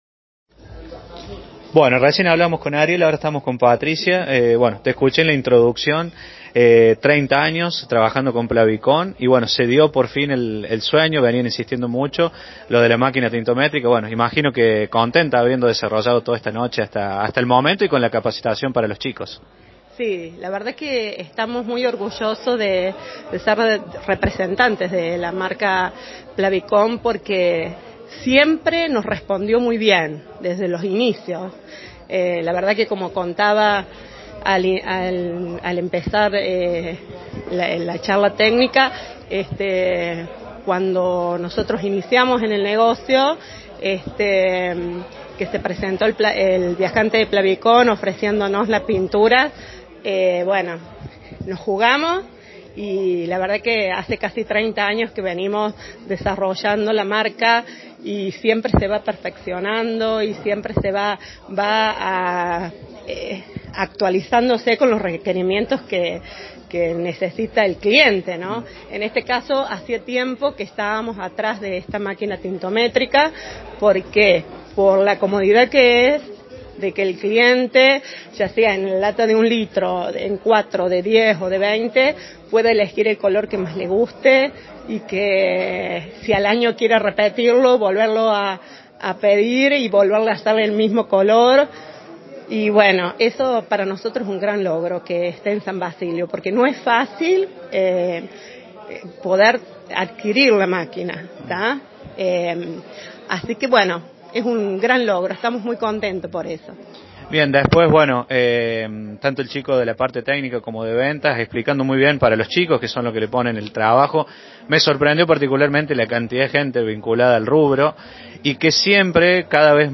que muy emocionada nos brindó su testimonio: